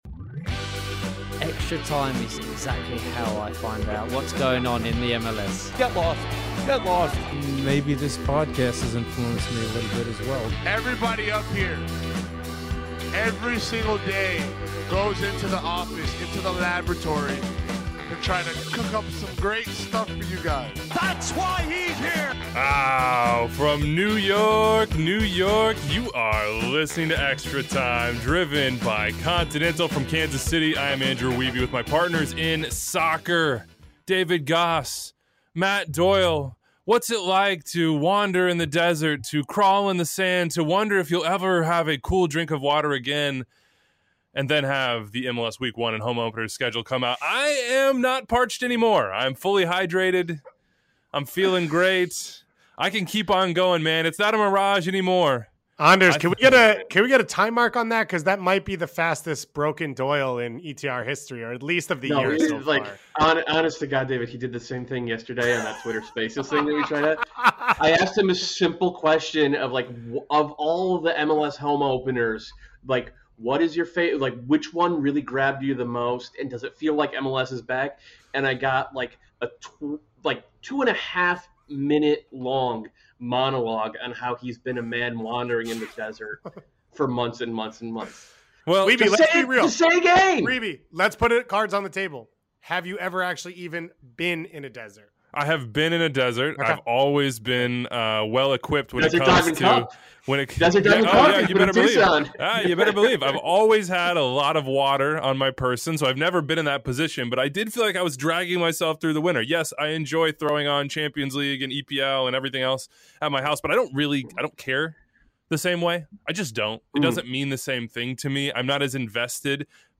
Bruce Arena calls in to give his take on the state of the national team, and go line by line...